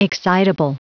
Prononciation du mot excitable en anglais (fichier audio)
Prononciation du mot : excitable